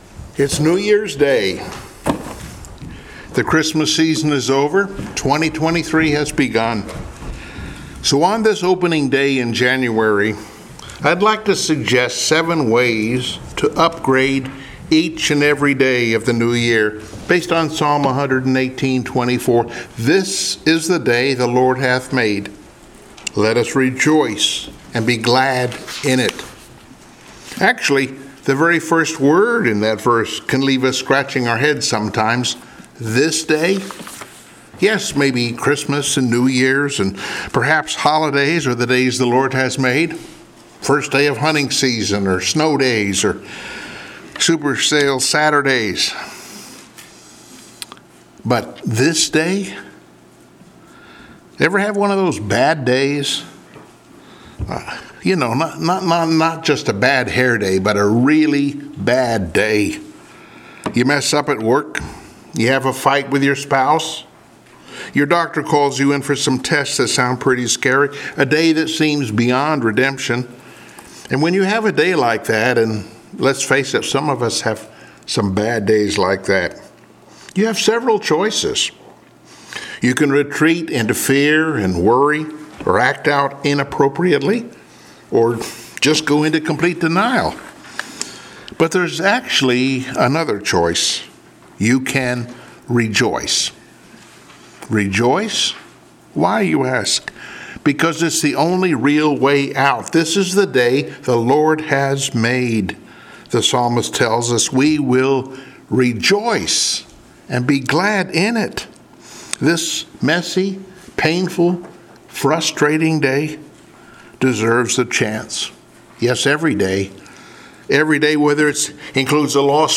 Service Type: Sunday Morning Worship